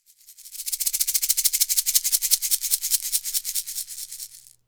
West MetroPerc (33).wav